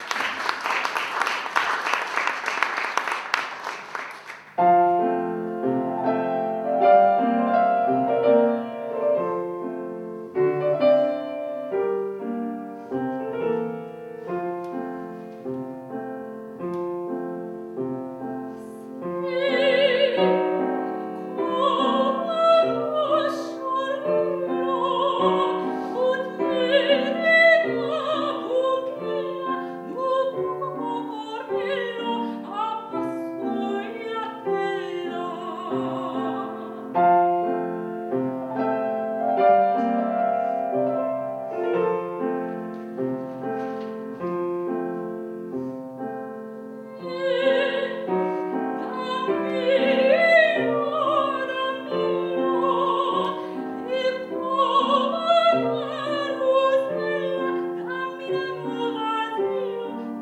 soprano
pianoforte